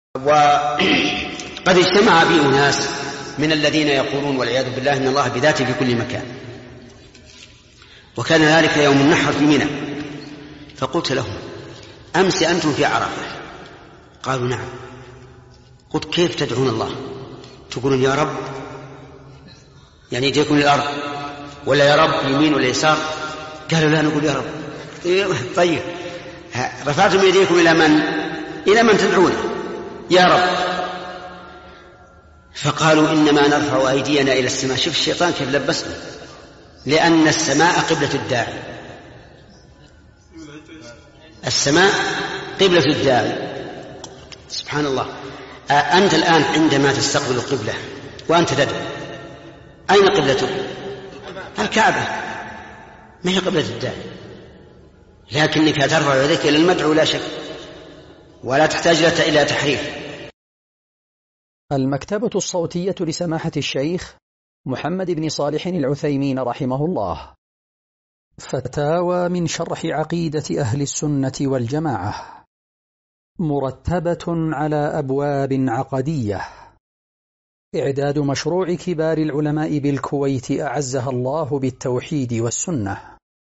[94 -850] مناظرة الشيخ في منى مع قوم أنكروا علو الله بذاته - الشيخ محمد بن صالح العثيمين